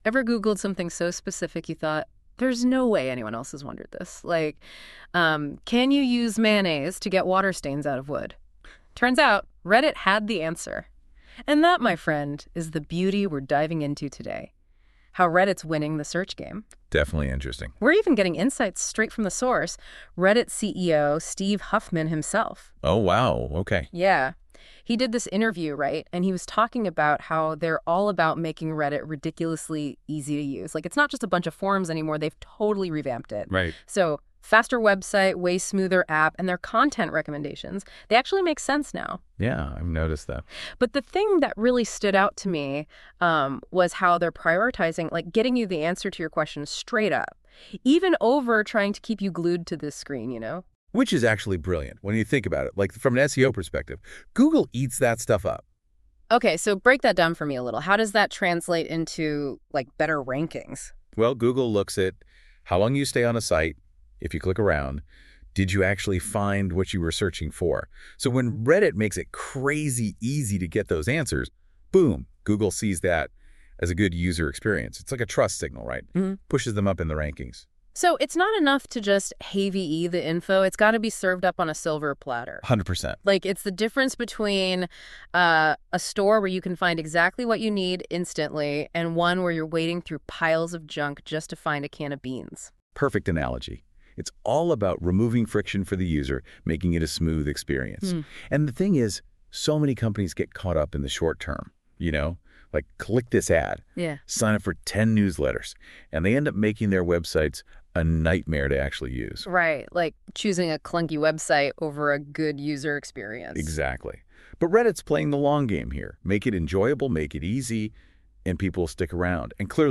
I optionally uploaded this Memo to NotebookLM’s new podcast feature, so you can listen to it if you like.